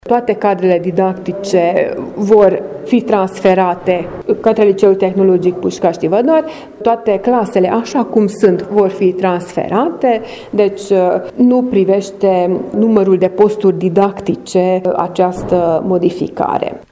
Viceprimarul municipiului Sfântu Gheorghe, Sztakics Eva a declarat că propunerea de reorganizare a reţelei şcolare are la bază un studiu, care a relevat, pe de o parte, o scădere considerabilă a numărului de elevi, iar, pe de alta, că infrastructura şcolară ar putea fi mai bine utilizată.